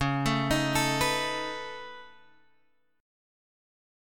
C# 7th Suspended 2nd Sharp 5th